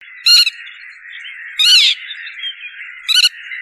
Barge rousse
Limosa lapponica
barge.mp3